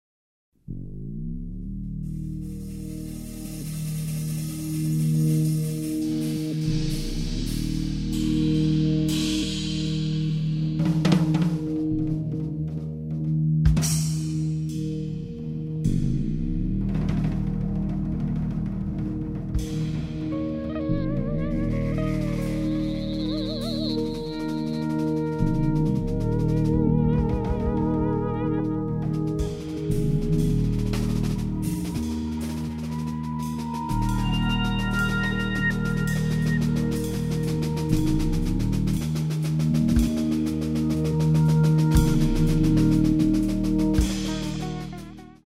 bassist and composer
on drums
on keyboards
on alto saxophone